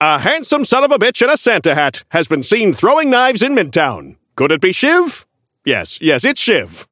Newscaster voice line - A handsome son of a bitch in a Santa hat has been seen throwing knives in midtown!
Newscaster_seasonal_shiv_unlock_01.mp3